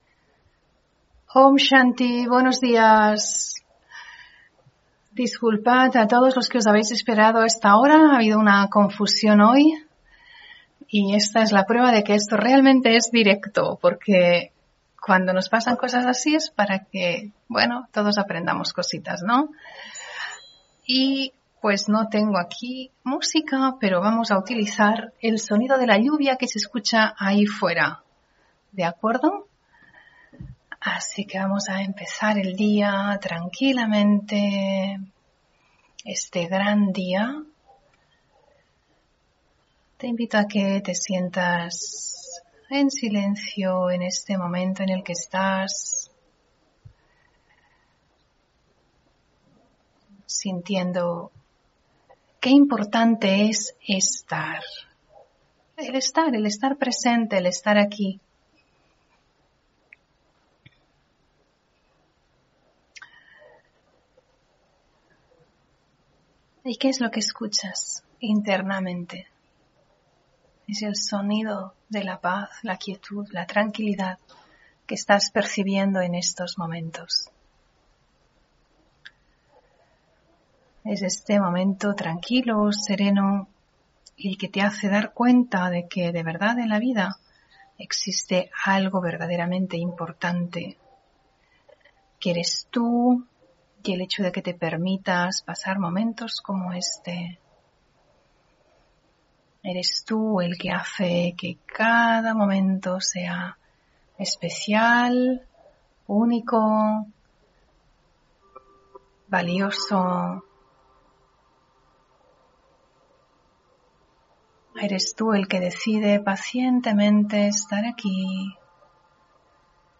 Meditación y conferencia: ¿Qué irradia la luz que eres? (22 Septiembre 2022)